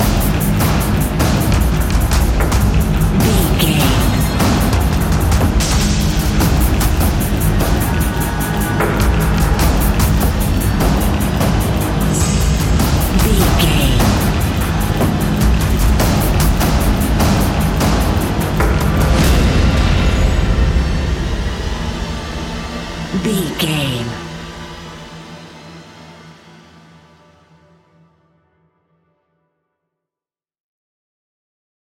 Fast paced
In-crescendo
Ionian/Major
industrial
dark ambient
EBM
synths
Krautrock